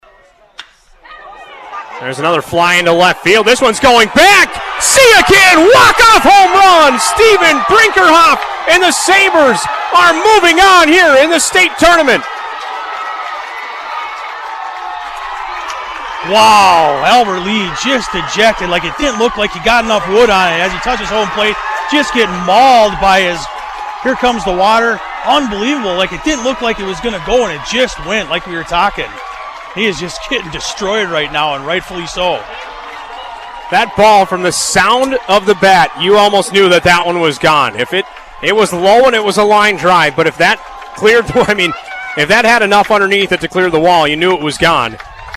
Walk-off homerun audio:
walk-off-hr.mp3